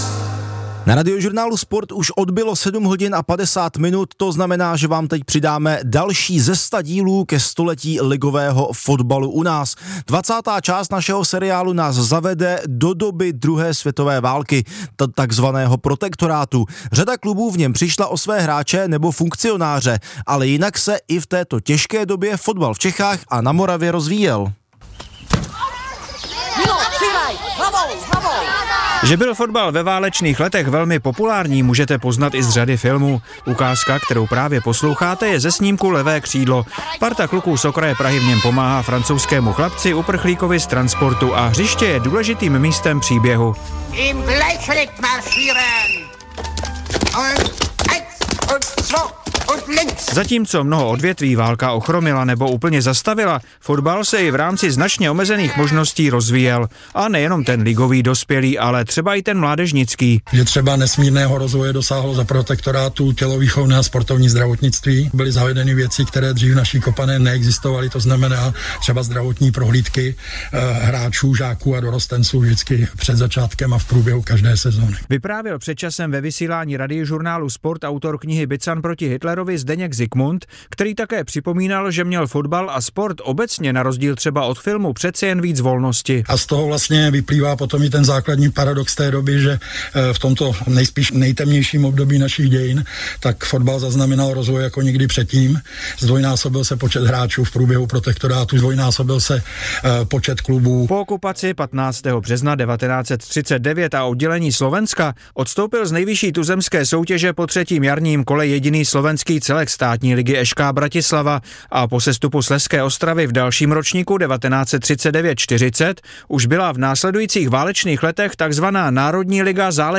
Na síti s Andreou Hlaváčkovou: Svého hosta se ptá bývalá tenistka, stříbrná medailistka ve čtyřhře z LOH 2012 v Londýně, dvojnásobná grandslamová šampionka ve čtyřhře (2013 US Open, 2011 French Open) - 16.09.2024